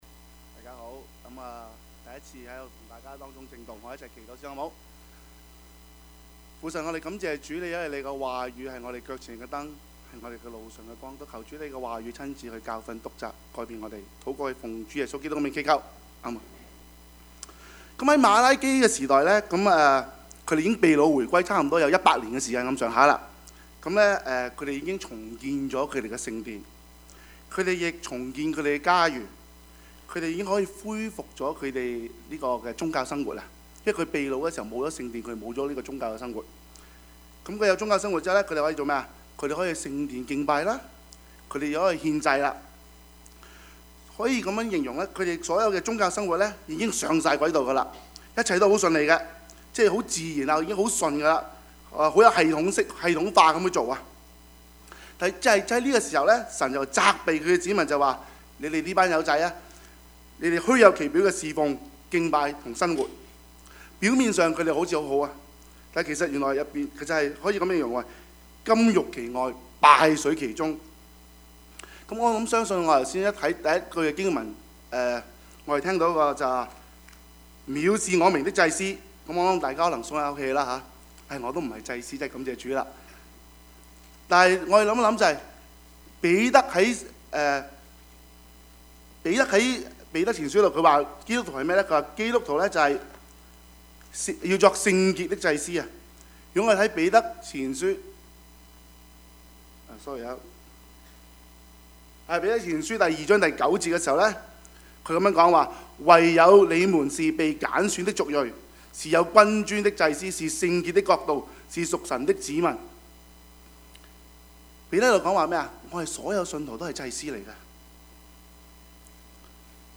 Service Type: 主日崇拜
Topics: 主日證道 « 神是信實的, 我們呢?